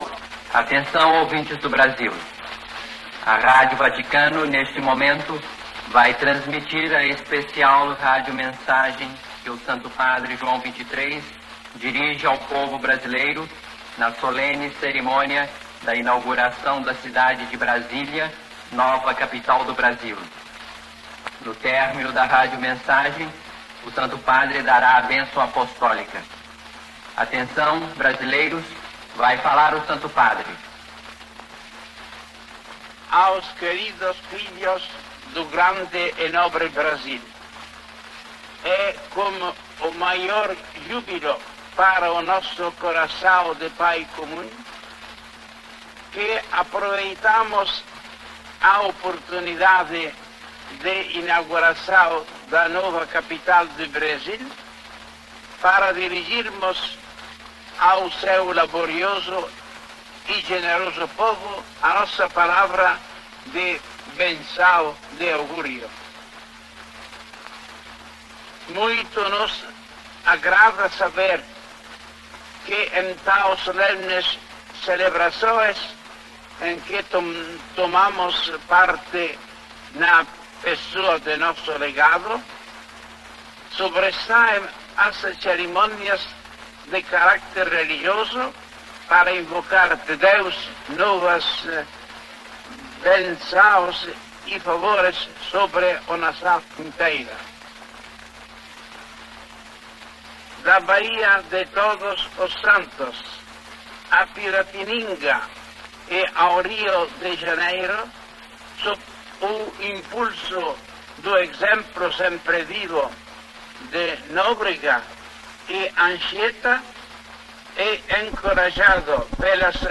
Mensagem_do_Papa_João_XXIII_ao_povo_brasileiro_–_edited.wav